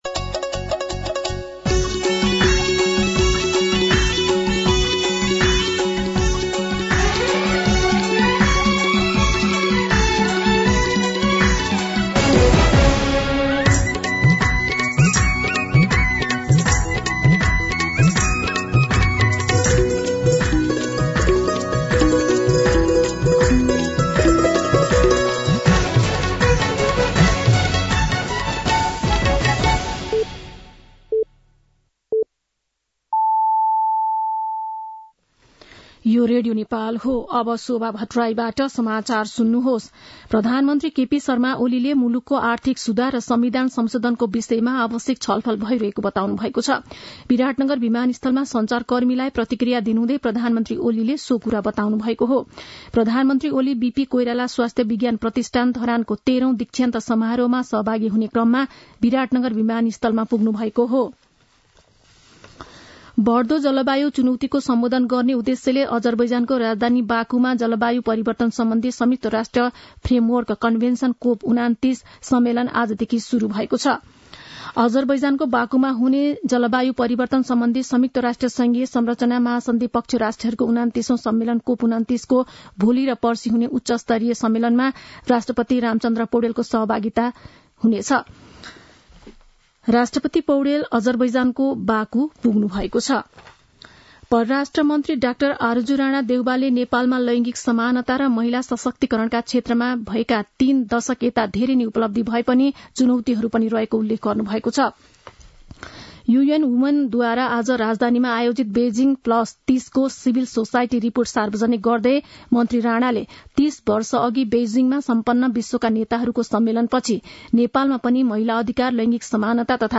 दिउँसो ४ बजेको नेपाली समाचार : २७ कार्तिक , २०८१
4pm-news-.mp3